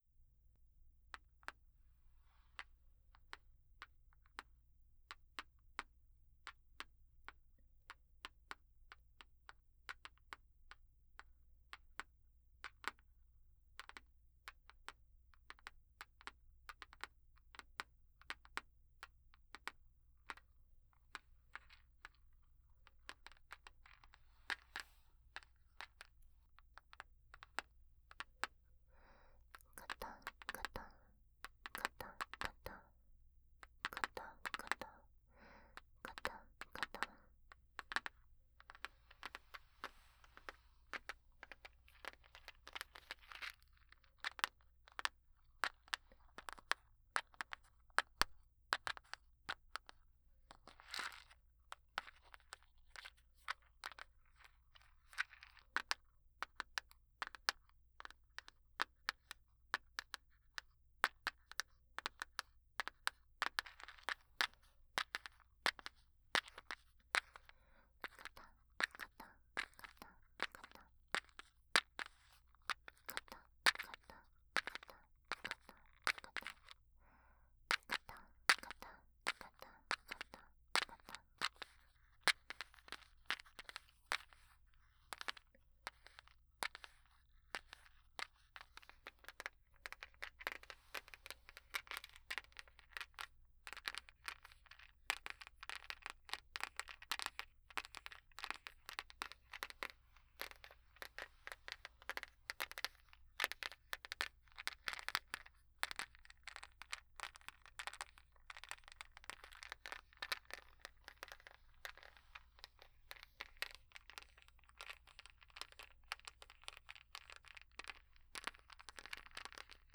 03.生活音パートのみ.wav